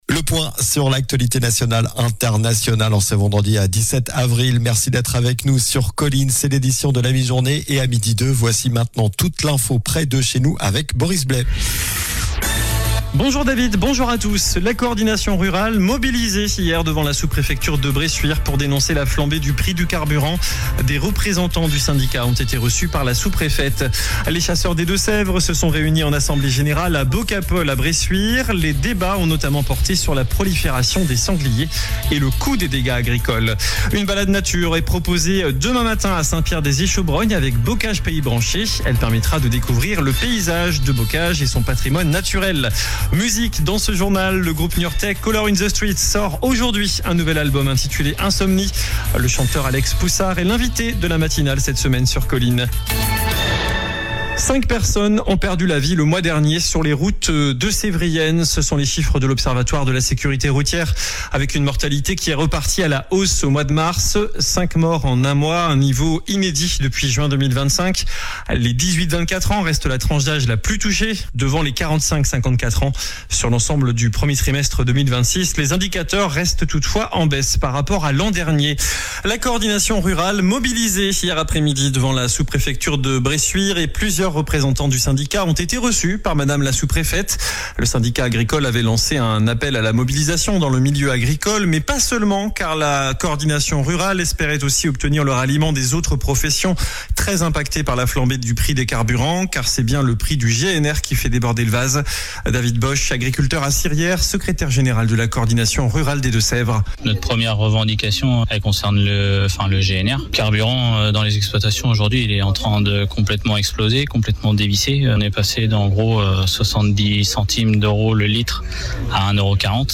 Journal du vendredi 17 avril (midi)